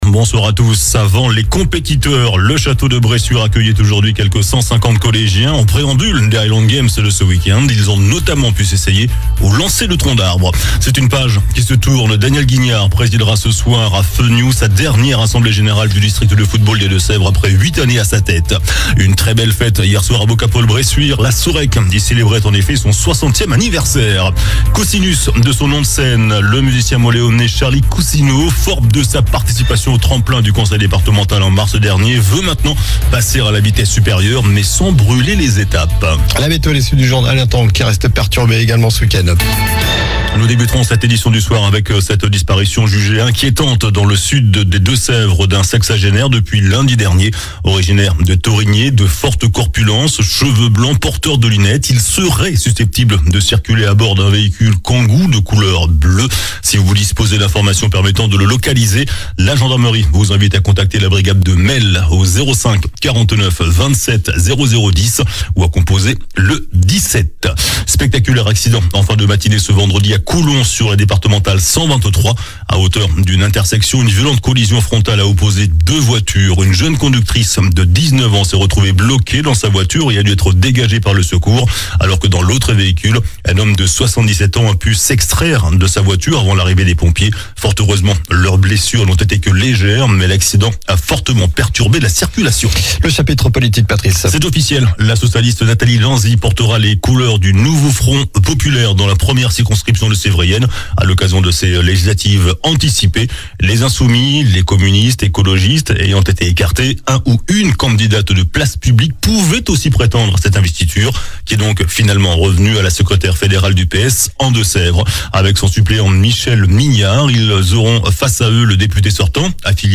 JOURNAL DU VENDREDI 14 JUIN ( SOIR )